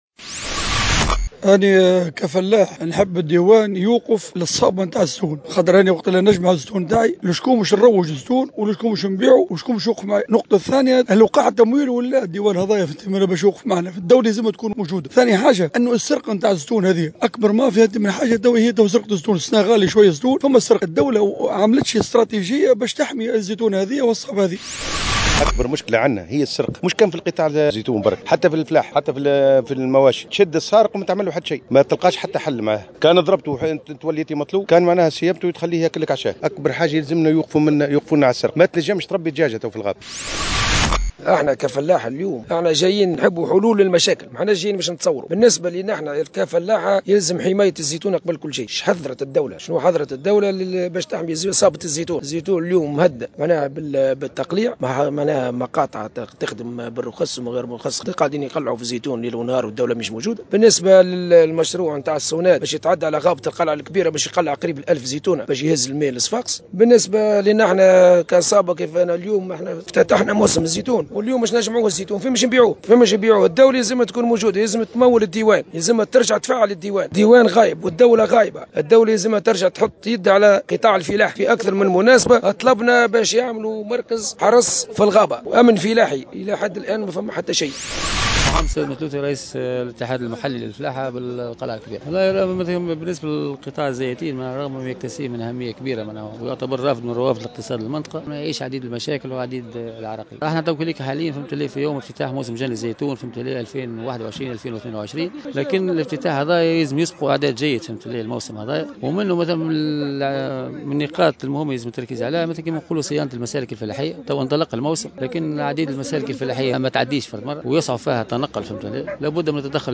أشرفت صباح اليوم الجمعة والي سوسة رجاء الطرابلسي، على الافتتاح الرسمي لموسم جني وتحويل الزيتون بمعتمدية القلعة الكبرى.
تذمر عدد من الفلاحين